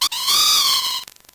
Cri_0581_NB.ogg(OGG, flac codec, longueur 1,1 s, 137 kb/s au total)